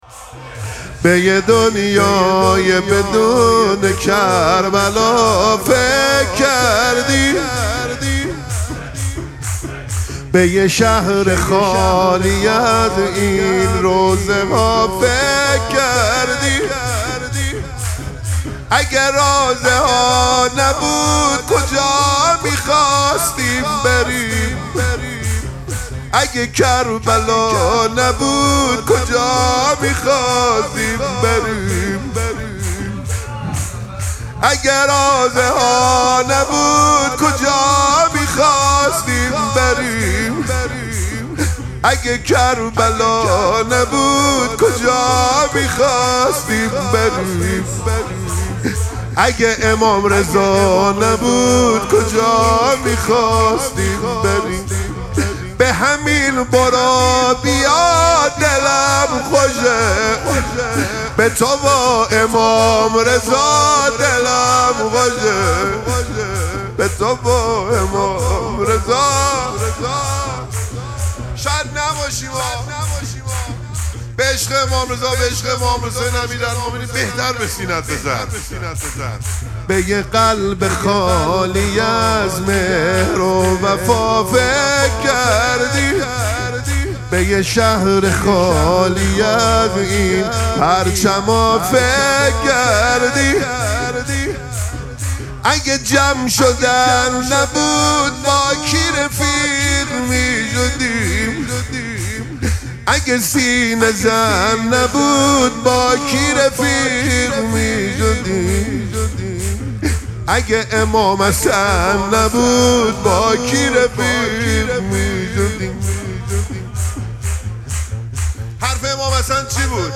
مراسم مناجات شب بیستم ماه مبارک رمضان
شور